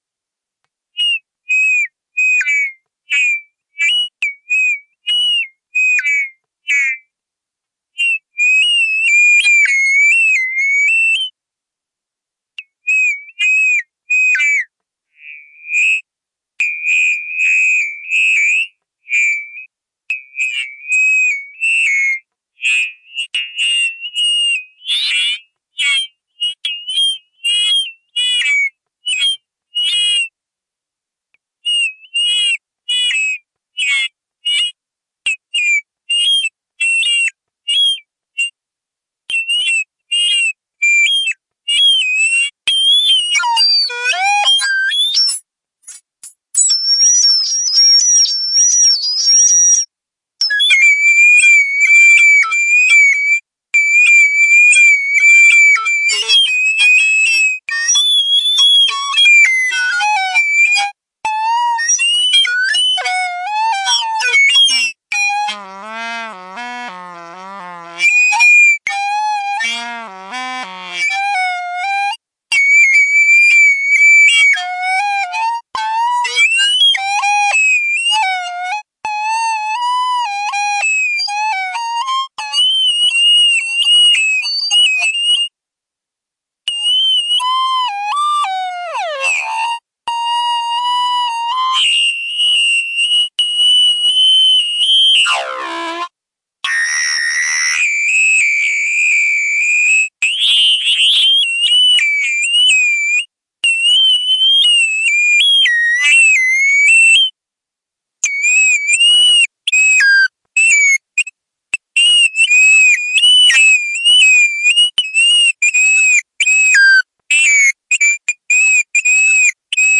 描述：一只（大部分）单身知更鸟在树顶上唱歌。
标签： 鸟鸣啁啾 户外 罗宾 独奏 鸣叫
声道立体声